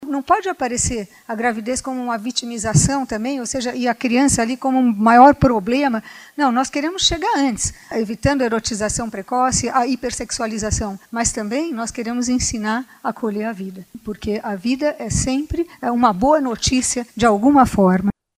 A secretária Nacional da Família, Ângela Gandra, defende o maior diálogo entre jovens e a família.
Sonora-Angela-Gandra-secretaria-Nacional-da-Familia.mp3